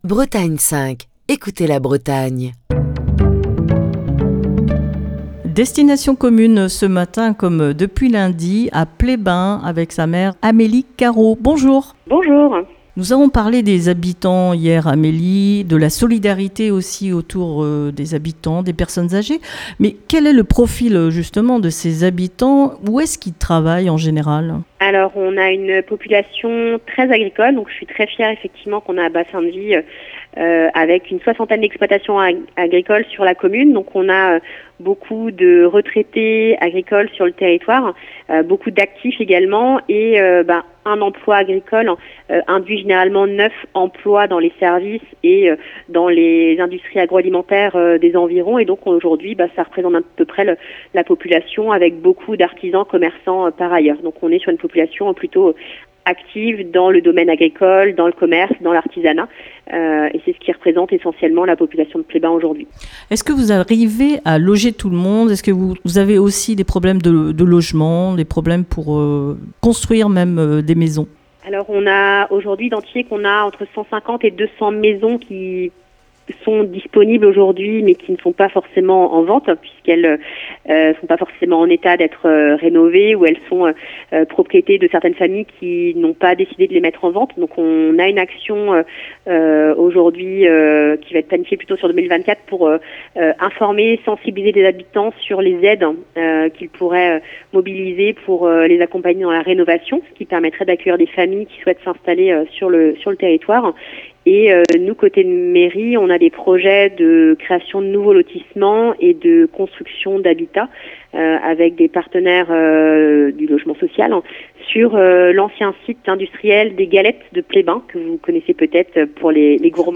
Cette semaine, Destination commune pose ses micros dans le Finistère à Pleyben.